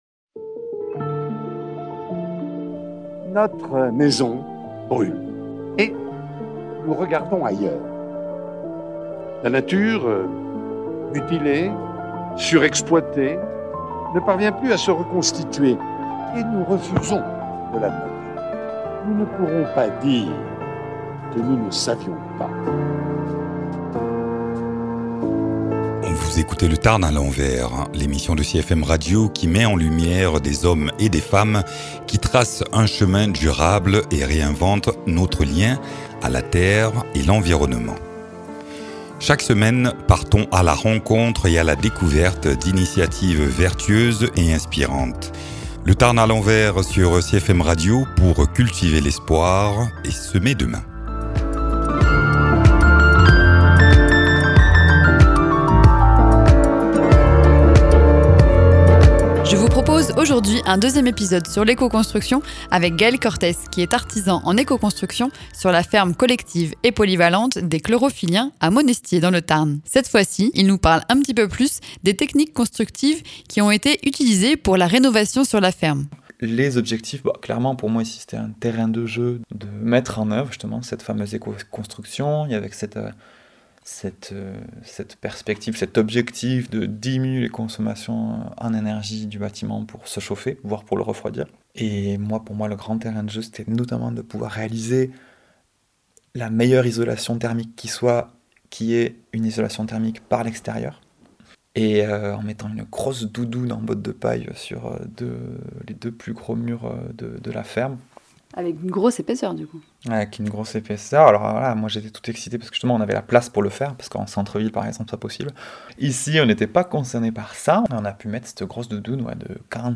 La ferme collective et polyvalente des Chlorophy-liens à Monestiés dans le Tarn met en œuvre plusieurs techniques d’éco-construction pour la rénovation du bâti ancien. Extrait d’un épisode de l’émission de CFM Cordes "Le Tarn Allant Vert"